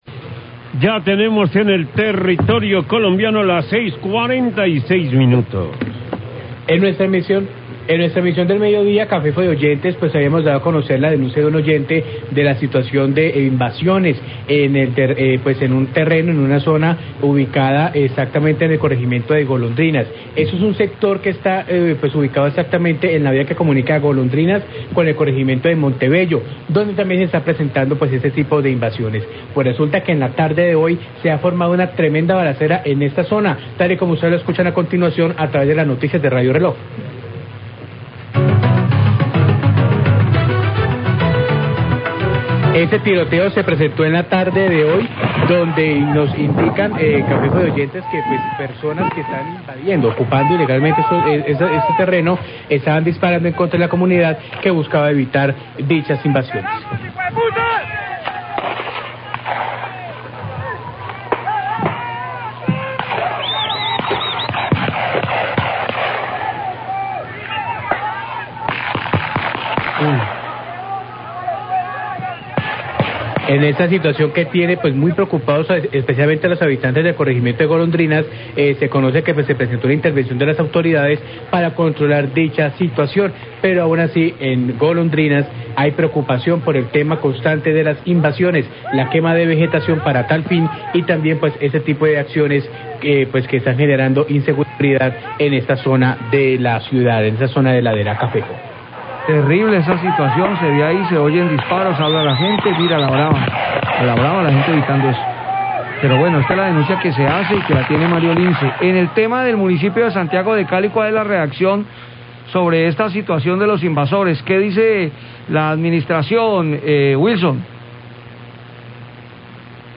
Radio
La administración municipal ha expresado su preocupación por la invasión de terrenos en cerros de Cali como en el corregimeinto de Golondrinas y el Cerro de la Bandera que degeneró en un enfrentameinto armado entre la comunidad vecina y los invasores. Habla el Subsecretario de Acceso a la Justicia, Aldemar Guevara.